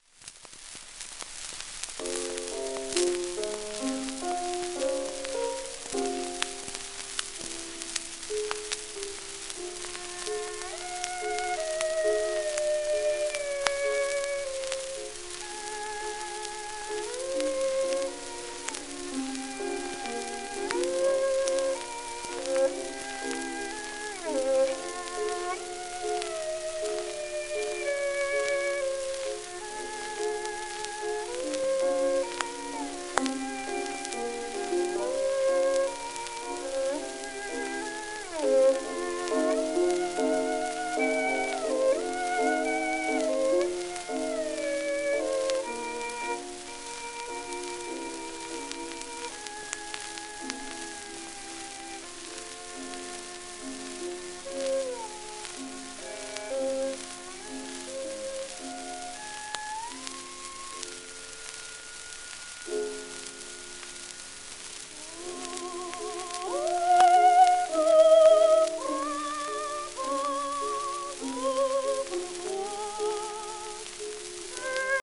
10インチ 片面盤
盤質A- *小キズ,薄いスレ,ややサーフェイスノイズ
1912年録音